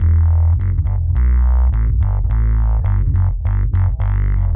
Index of /musicradar/dub-designer-samples/105bpm/Bass
DD_PBassFX_105E.wav